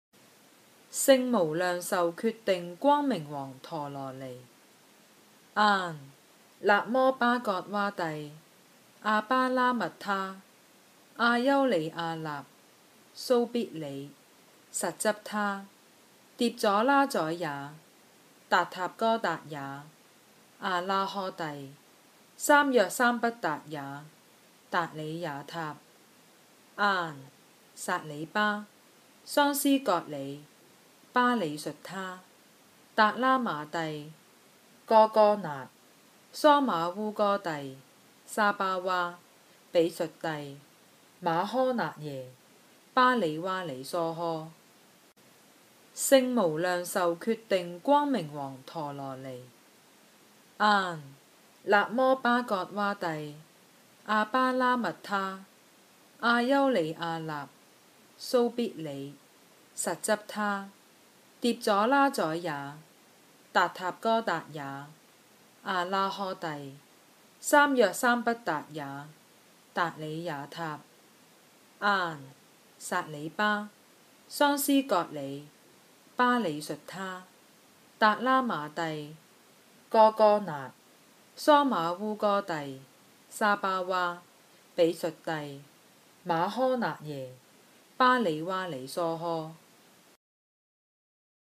《圣无量寿决定光明王陀罗尼》经文教念粤语版